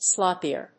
/ˈslɑpiɝ(米国英語), ˈslɑ:pi:ɜ:(英国英語)/